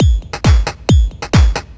DS 135-BPM C2.wav